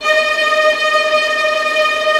VIOLINT FN-L.wav